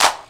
Clap 12.wav